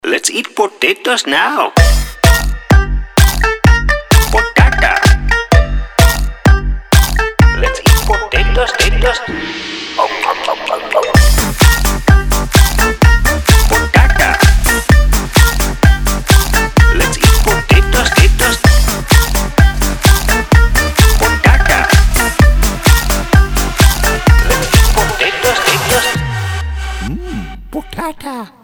• Качество: 320, Stereo
ритмичные
забавные
веселые
bounce
с хрустом чипсов